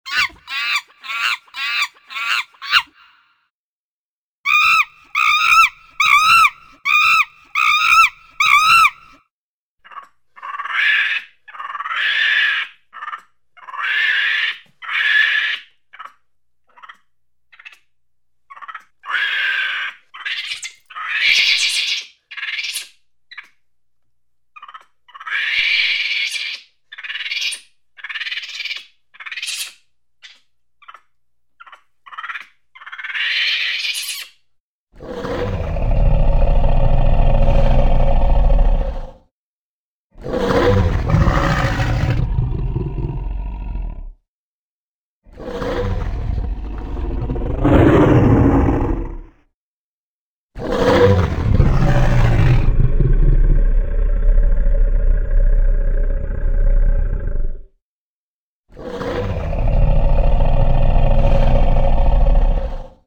• Качество: высокое
Разнообразие звуков голосов обезьян